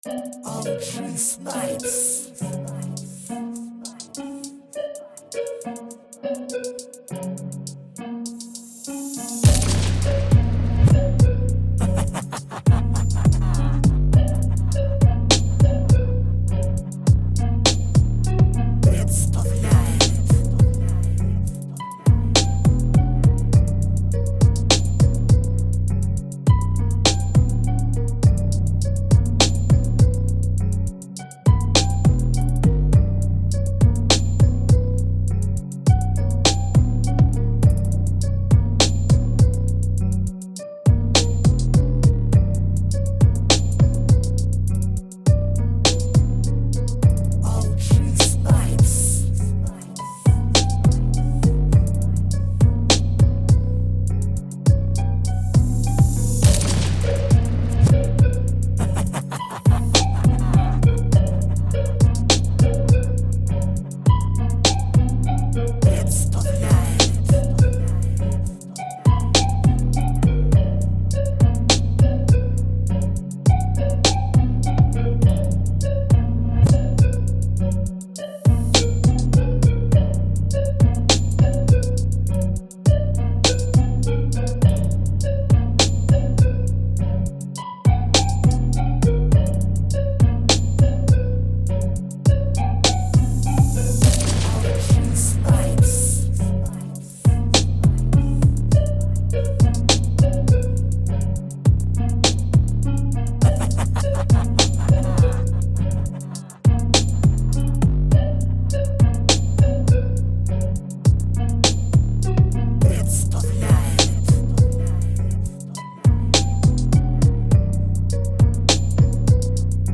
Ghost Warrior TRap Beat